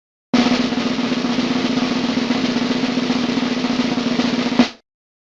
ruleta.mp3